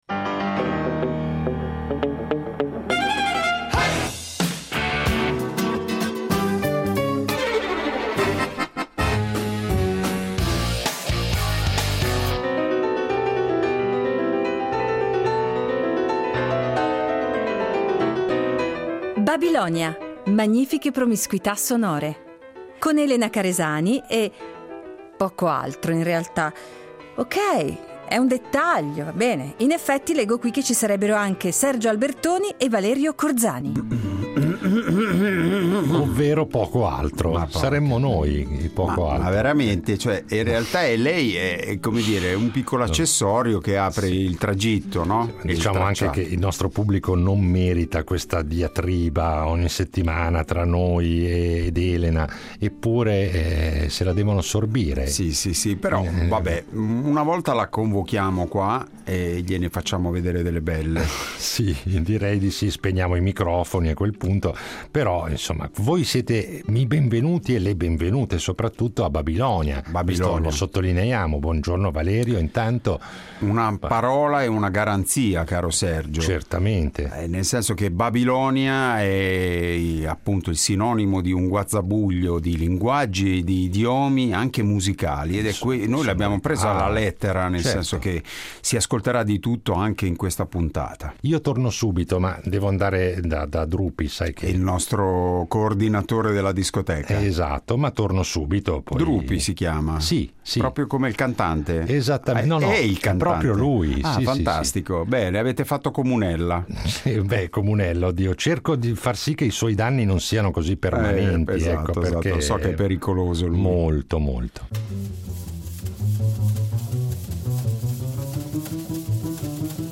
La nostra arca radiofonica veleggia sulle ali dell’entusiasmo, del piacere che i due conduttori alimentano da sempre di snocciolare scalette babiloniche, ovvero eccentriche e sorprendenti perché libere di scorrazzare tra i generi musicali più disparati.